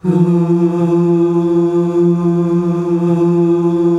HUUUH   F.wav